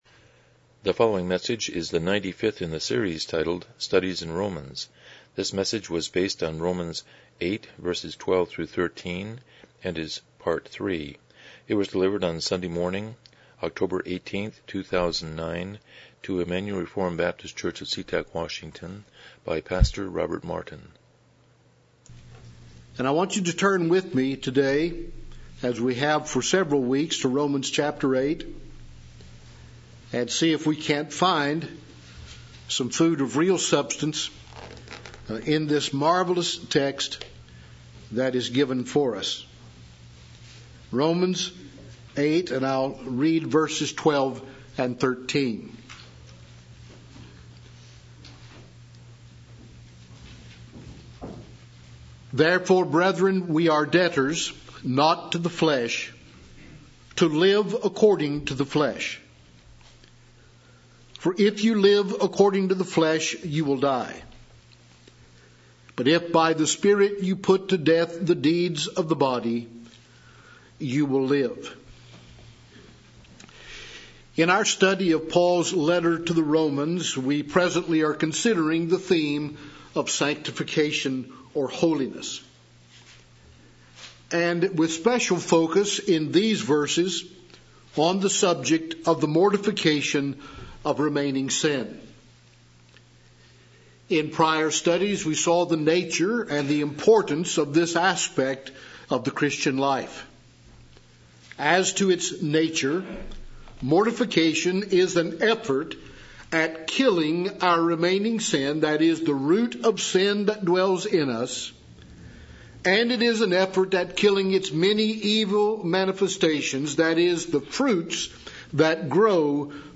Passage: Romans 8:12-13 Service Type: Morning Worship